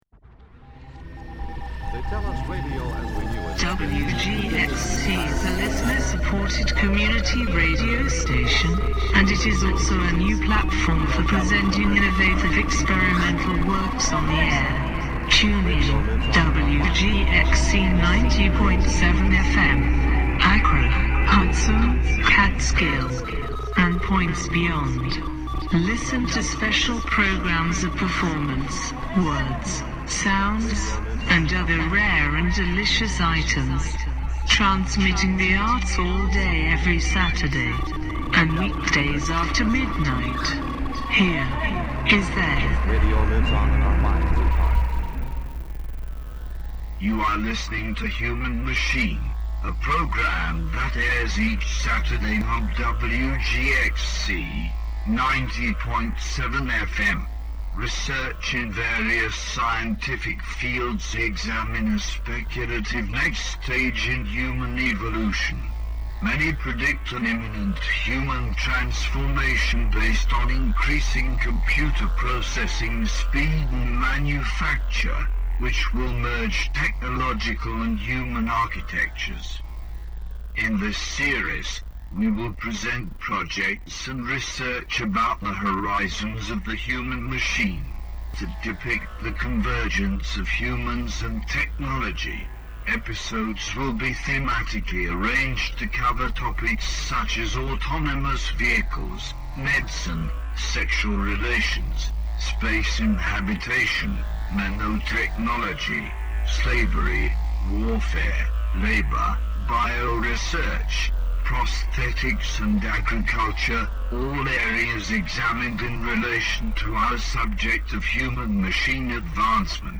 Human Machine is a hour-long radio broadcast